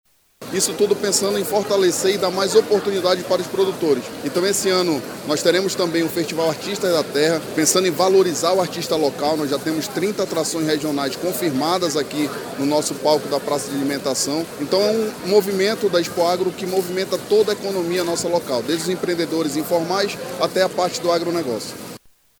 A realização da 47ª edição da Exposição Agropecuária do Amazonas (Expoagro) conta com uma série de ações até o dia 5 de outubro com exposição de animais, cursos e palestras, rodada de negócios e científica, feira de produtos regionais, shows musicais, rodeio e competições equestres, cursos leiteiros de raças, programação e espaço kids, acesso ao crédito rural, comercialização de maquinários, gastronomia regional, artesanato indígena, entre outros, com a presença de 580 estandes, como explica o secretário da Sepror, Daniel Borges.